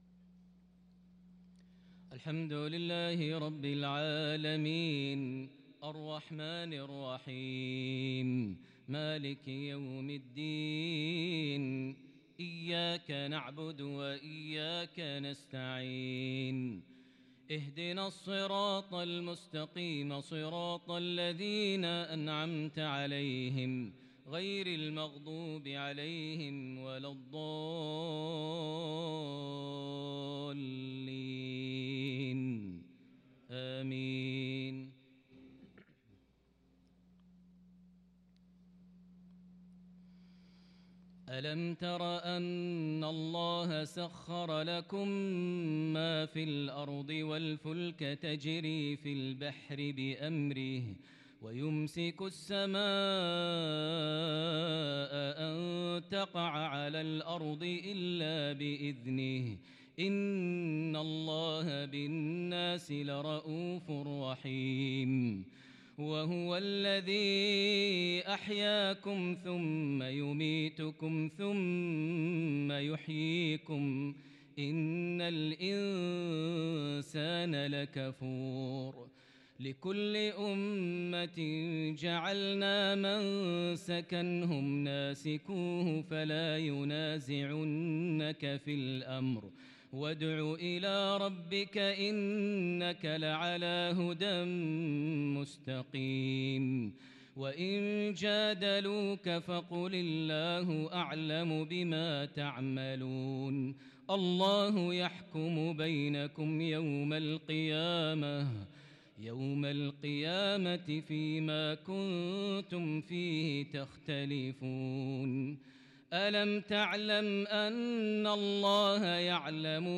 صلاة العشاء للقارئ ماهر المعيقلي 28 صفر 1443 هـ